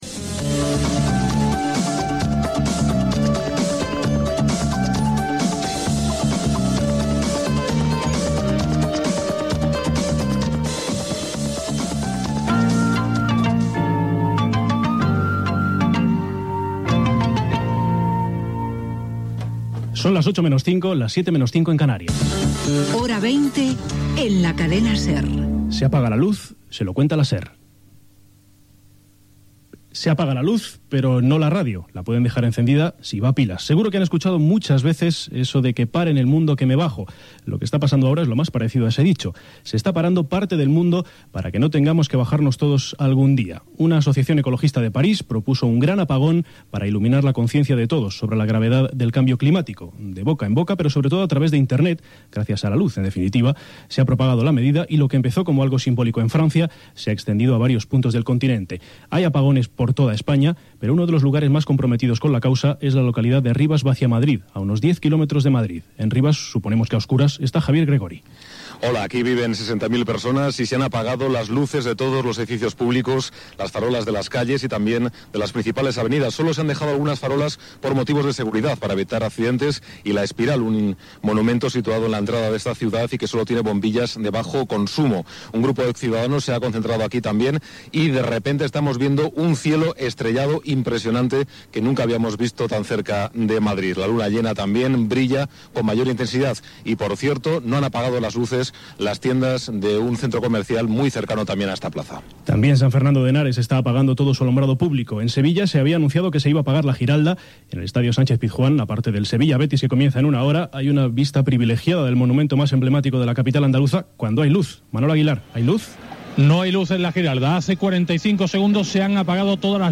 Hora, careta, informació de l'apagada voluntària internacional de la llum per conscienciar de l'excessiu consum energètic. Connexions amb diversos punts de l'Estat espanyol, Brusel·les i París
Informatiu